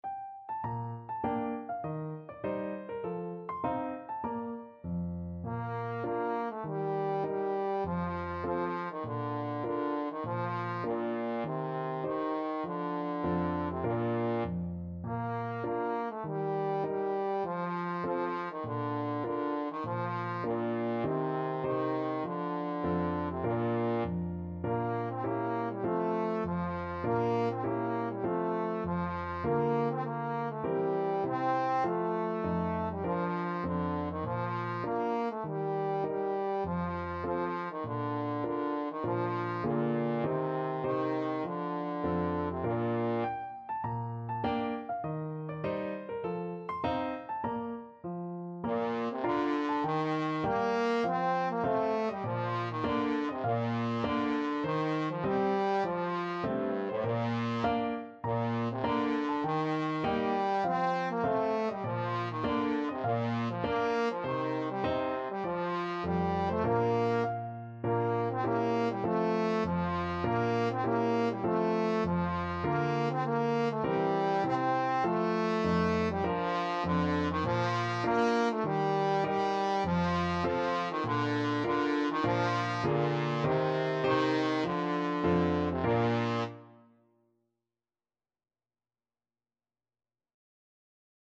Traditional Trad. Country Gardens Trombone version
Trombone
4/4 (View more 4/4 Music)
Bb major (Sounding Pitch) (View more Bb major Music for Trombone )
Moderato
Traditional (View more Traditional Trombone Music)